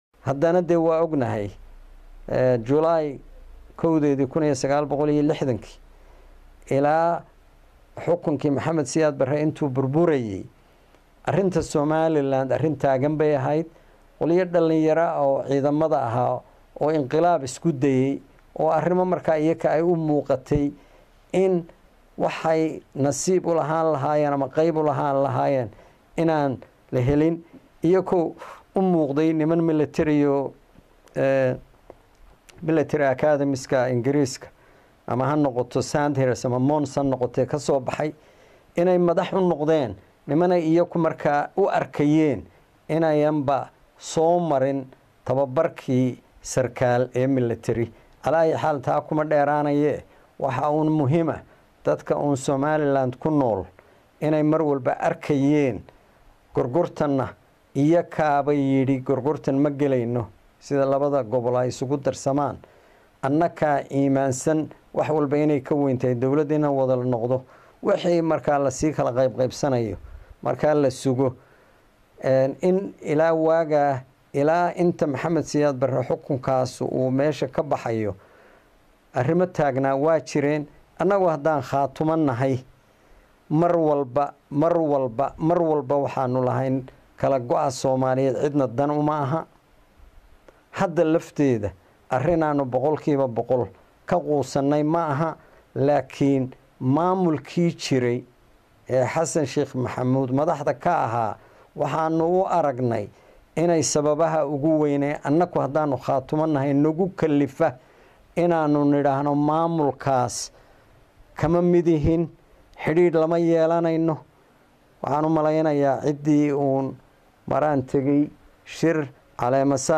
Dhagayso Galayr oo sharaxaya qorshihiisa wada hadalada Somaliland & Khaatumo